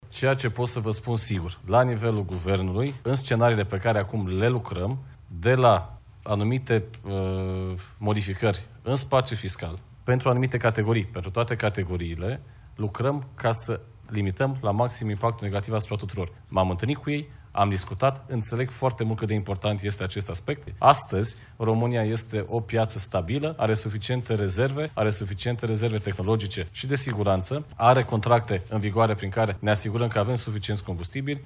Ministrul Energiei, Bogdan Ivan: „România este o piață stabilă”